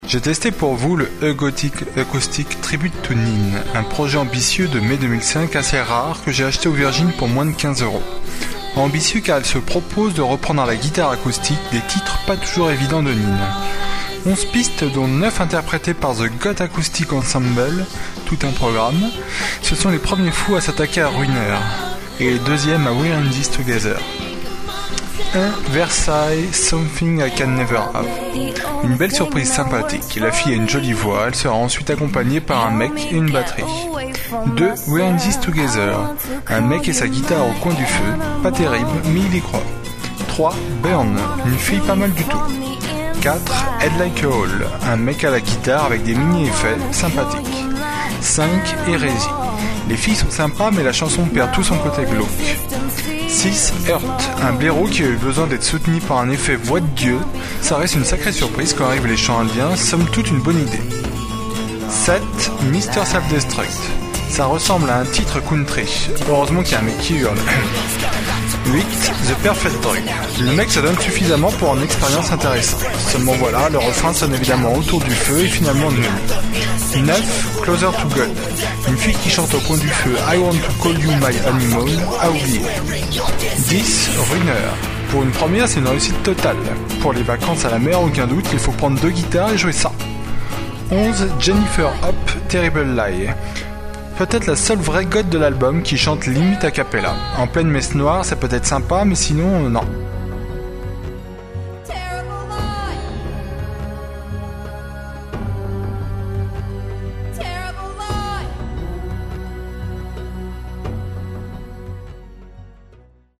Ambitieux car il se propose de reprendre à la guitare acoustique des titres pas toujours evidents de nin.